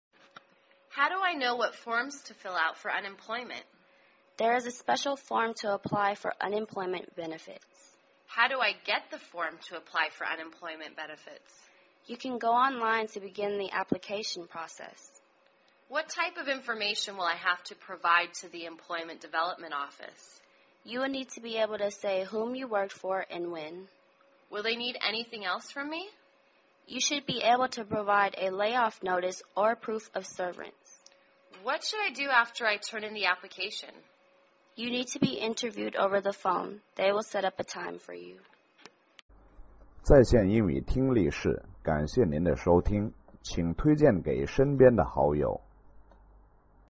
失业英语对话-Forms to Fill Out(2) 听力文件下载—在线英语听力室